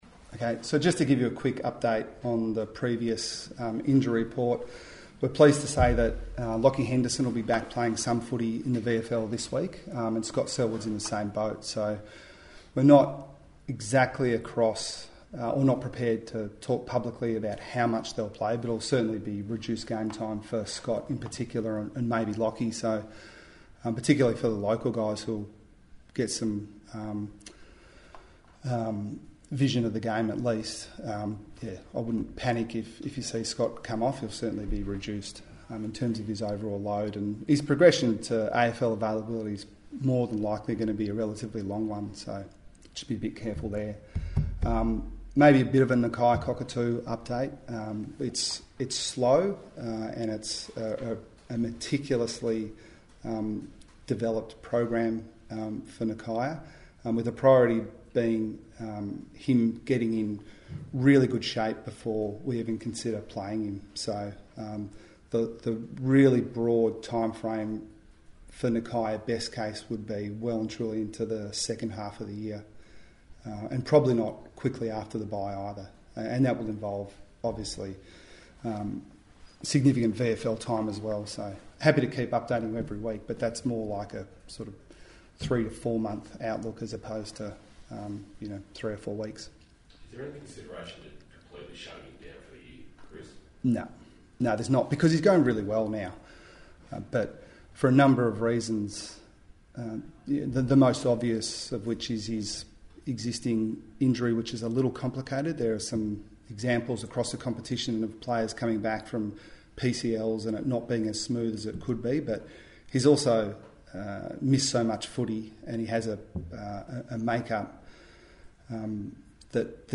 Geelong coach Chris Scott faced the media ahead of Sunday's clash with Essendon at the MCG.